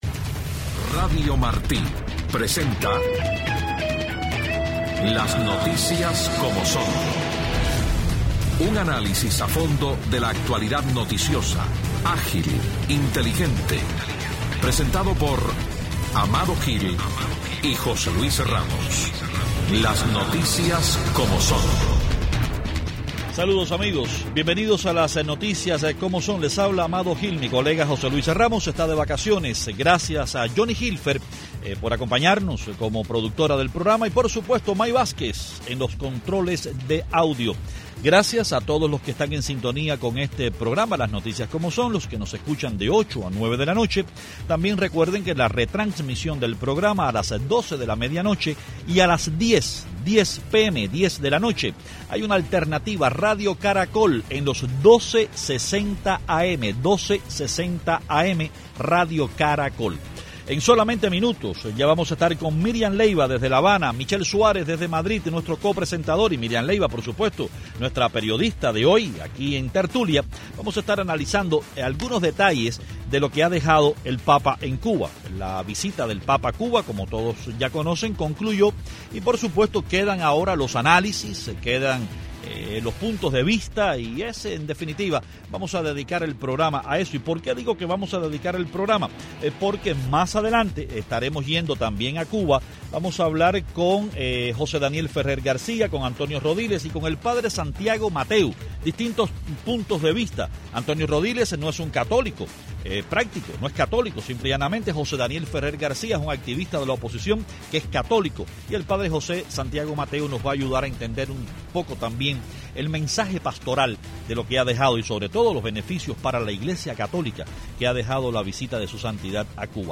Una interesante tertulia con los periodistas